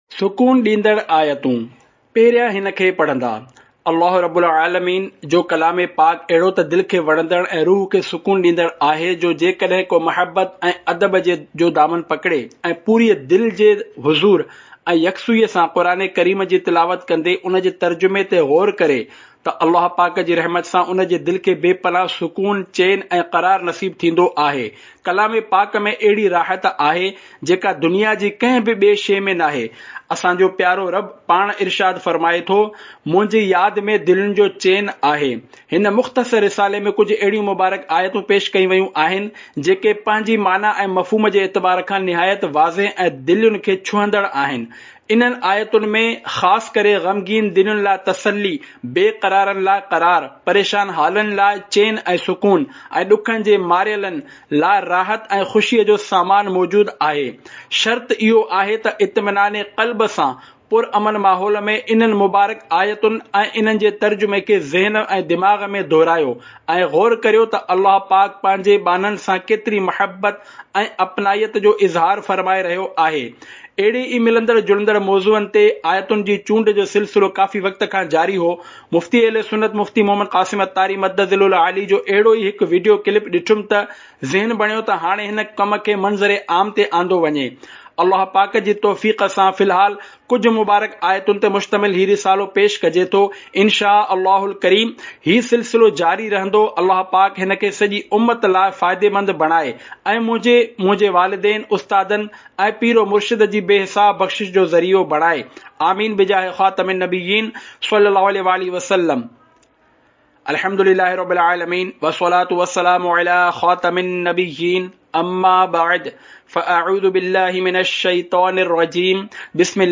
Audiobook - Sukoon Bakhsh Aayaten (Sindhi)